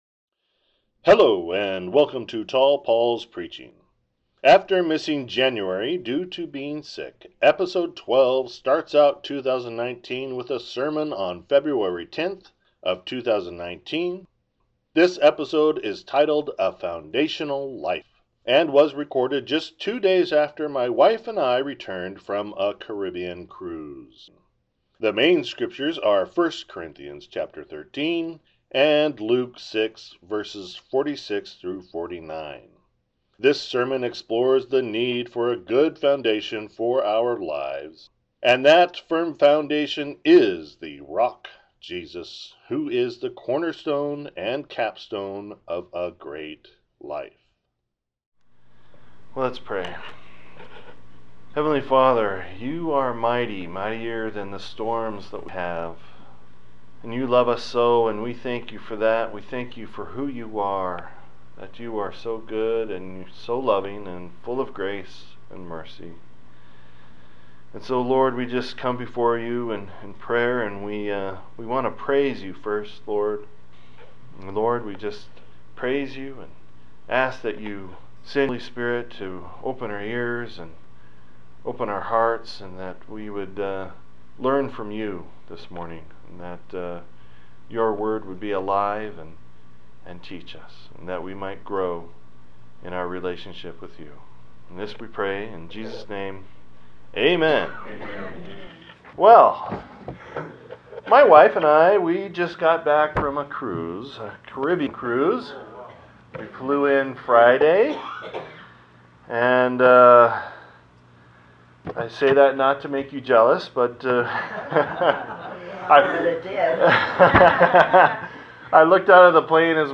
This is the first sermon for 2019 and it explores the need for a good foundation for our lives and that firm foundation is the rock Jesus who is the cornerstone and capstone of a great life.